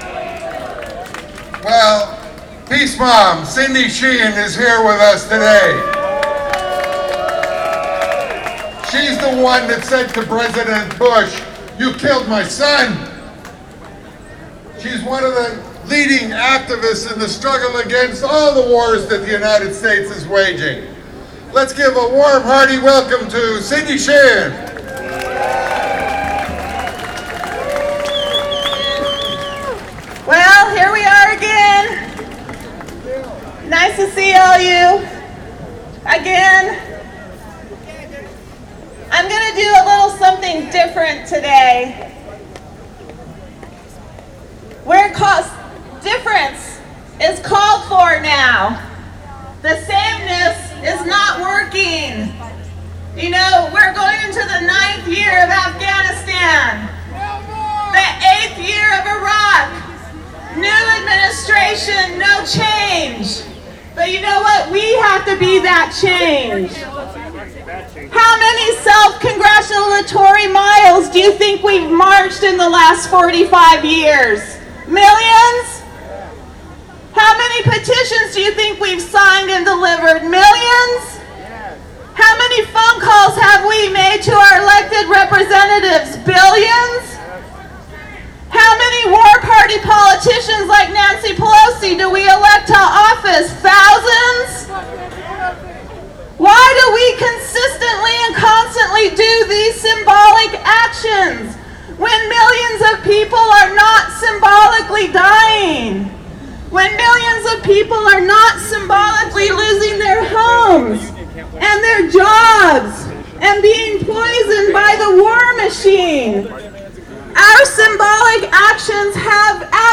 Photos And Some Audio From Oct 17th Anti-War Protest in San Francisco
§Audio Of Cindy Sheehan Speaking